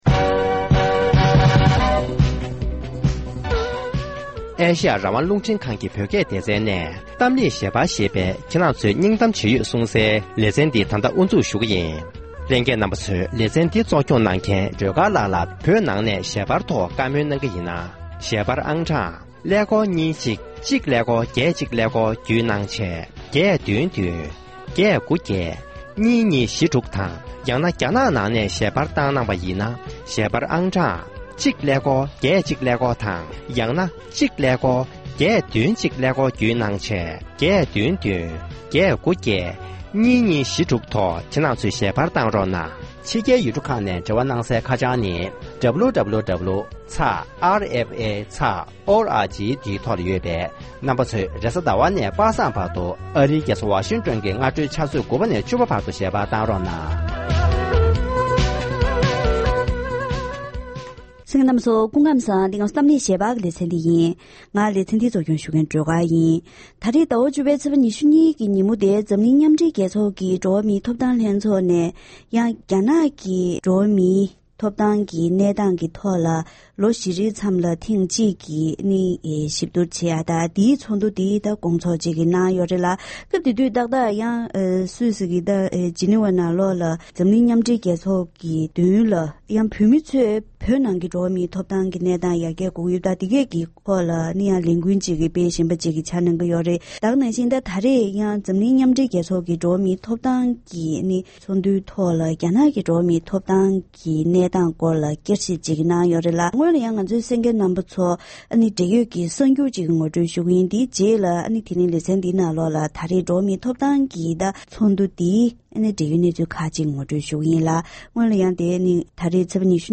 ༄༅༎དེ་རིང་གི་གཏམ་གླེང་ཞལ་པར་ལེ་ཚན་ནང་འཛམ་གླིང་མཉམ་སྦྲེལ་རྒྱལ་ཚོགས་ཀྱི་འགྲོ་བ་མིའི་ཐོབ་ཐང་ལྷན་ཚོགས་ནས་རྒྱ་ནག་གི་འགྲོ་བ་མིའི་ཐོབ་ཐང་གནས་སྟངས་ལ་ཚེས་༢༢ཉིན་བསྐྱར་ཞིབ་གནང་ཡོད་པས། བོད་དང་འབྲེལ་ཡོད་ཀྱི་གནས་སྟངས་ཐོག་བགྲོ་གླེང་ཇི་འདྲ་བྱུང་མིན་ཐད་བཀའ་མོལ་ཞུས་པ་ཞིག་གསན་རོགས་གནང་།།